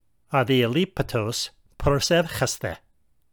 adialeiptōs proseuchesthe